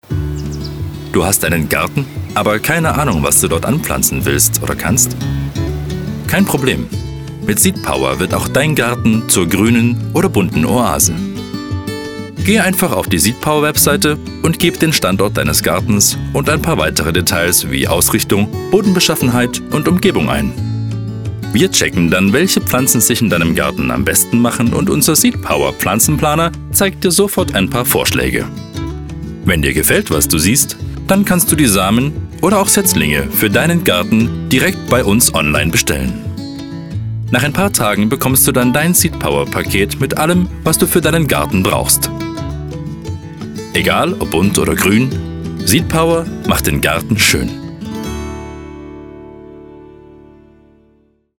Ihr Sprecher für Audioguide, Werbung/Image, Dokumentation, Off-Voice, Industrie, eLearning, Hörbuch und mehr.
Sprechprobe: eLearning (Muttersprache):